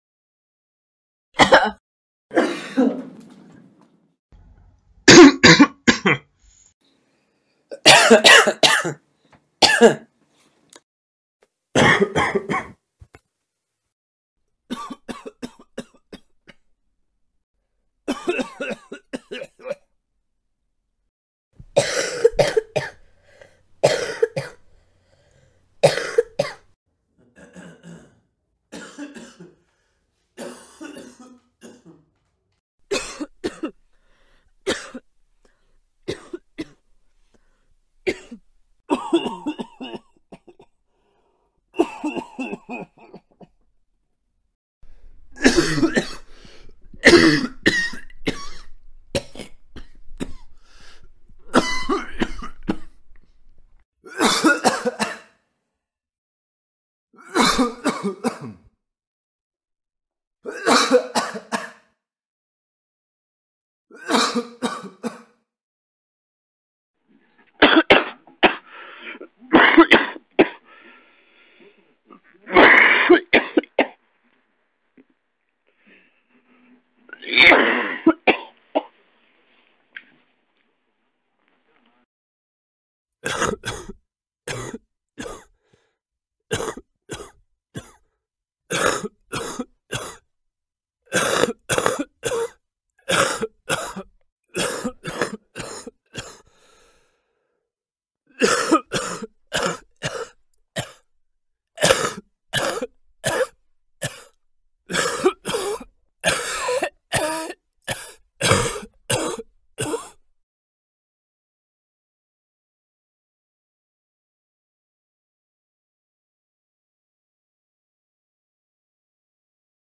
دانلود صدای سرفه – مرد،زن و بچه 3 از ساعد نیوز با لینک مستقیم و کیفیت بالا
جلوه های صوتی